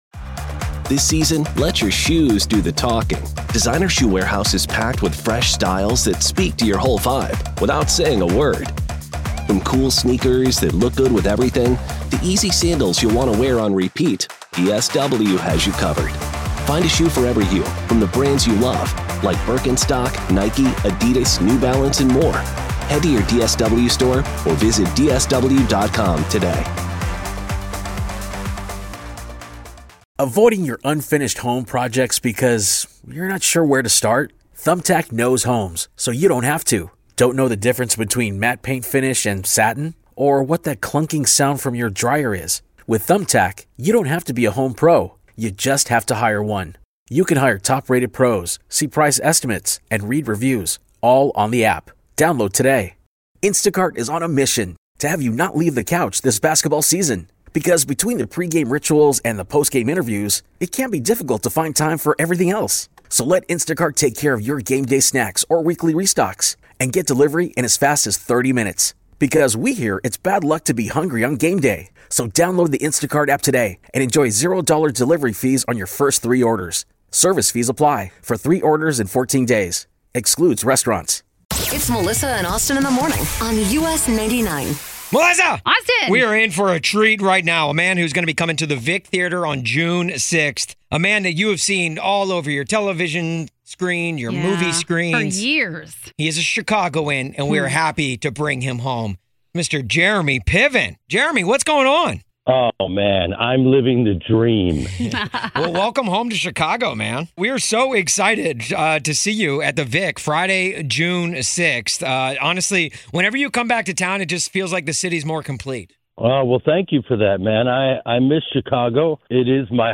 chatted with Actor/Comedian Jeremy Piven